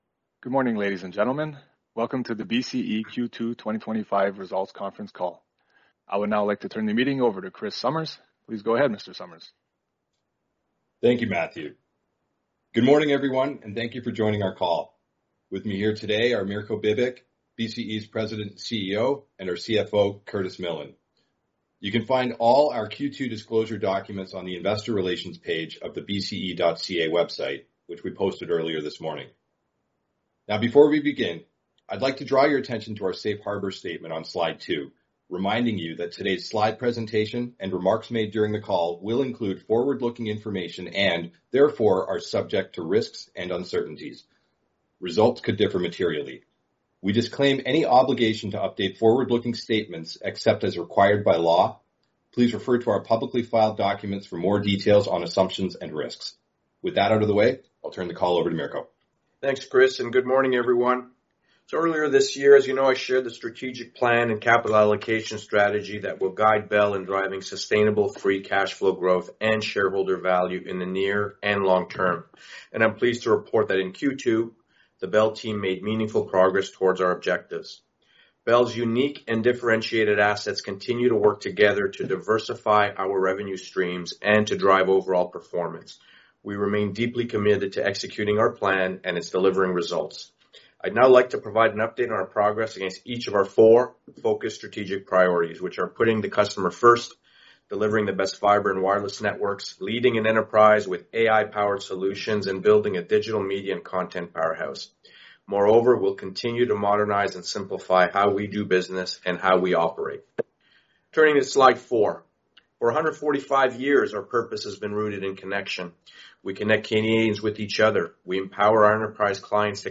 Téléconférence sur les résultats de BCE pour le T2 2025